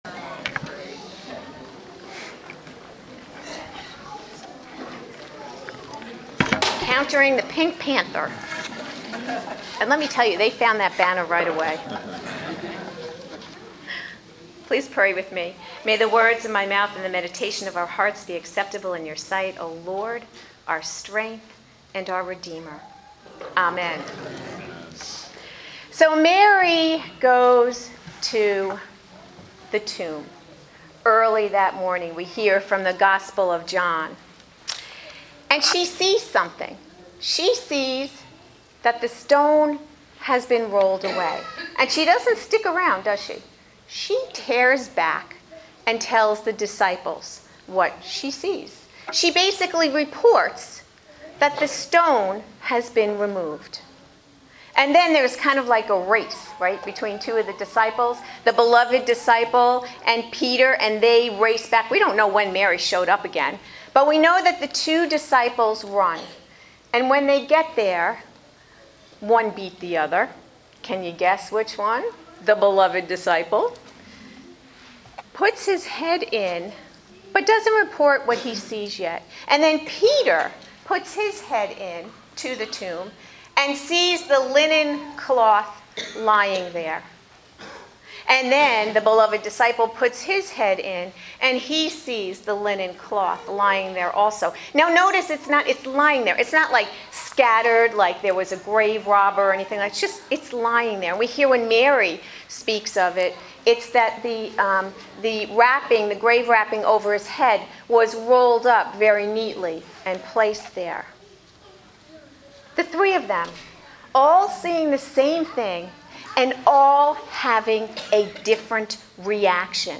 preaches her Easter Message “Just Like Mary”